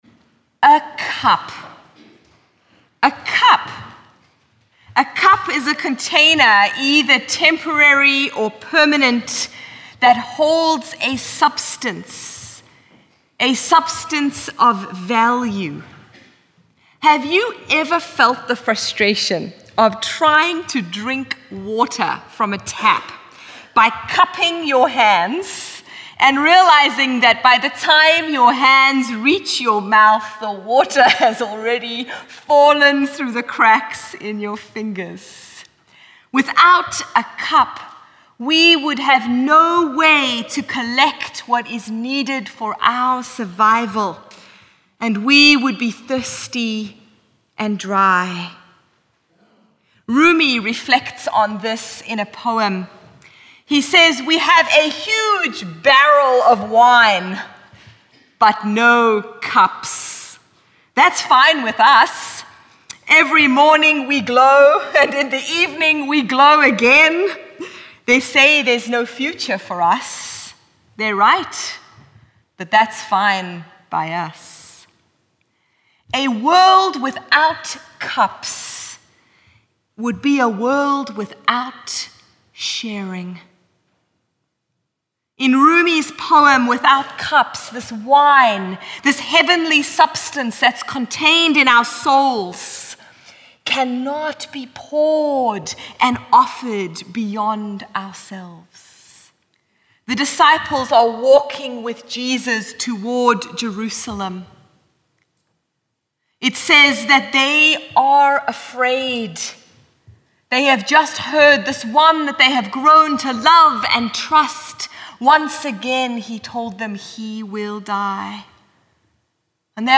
Twenty-second Sunday after Pentecost 2018
Sermon Notes